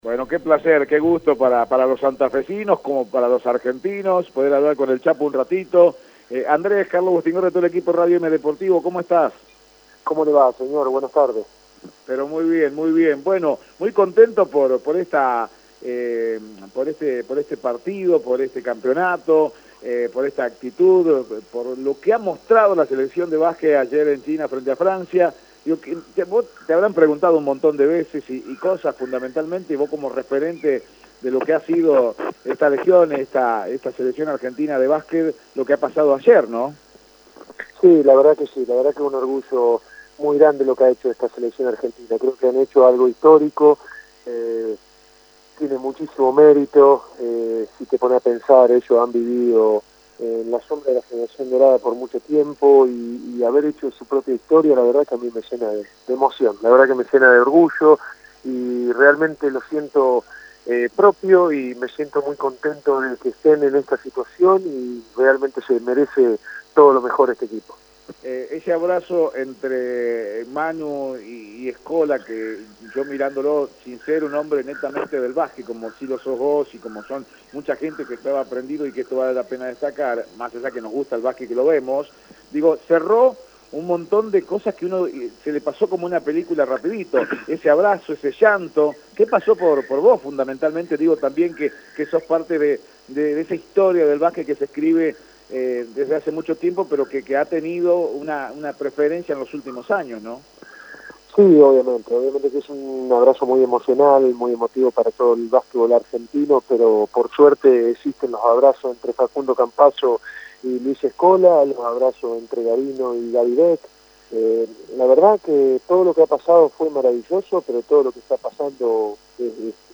En diálogo con Radio Eme Deportivo, en la previa del encuentro entre Colón y San Lorenzo de Almagro, un ex selección, medalla dorada en los juegos Olímpicos 2004, Andrés “Chapu” Nocioni habló acerca del equipo y de la nueva generación.